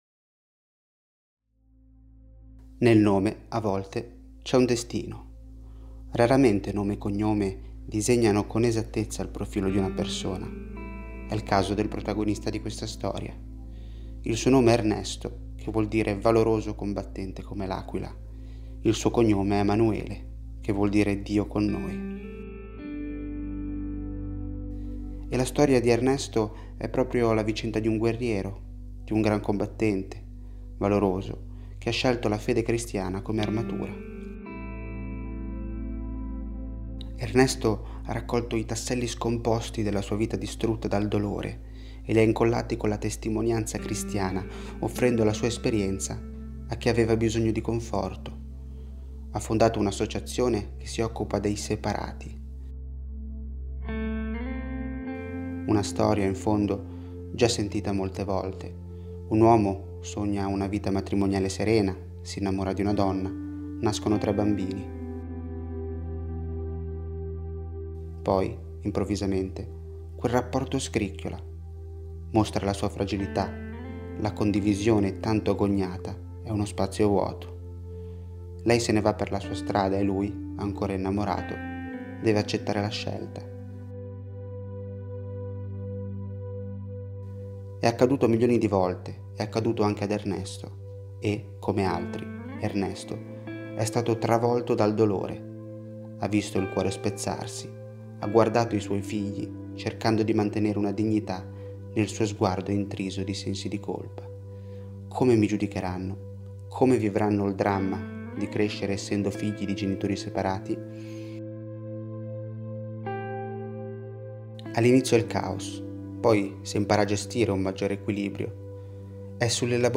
Storie > Audioletture